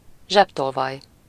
Ääntäminen
Synonyymit zsebmetsző zsebes Ääntäminen Tuntematon aksentti: IPA: /ˈʒɛbtolvɒj/ Haettu sana löytyi näillä lähdekielillä: unkari Käännös Substantiivit 1. pickpocket Luokat Ihmiset Rikollisuus Substantiivit Yhdyssanat